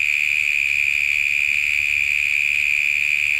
sounds_cicada_03.ogg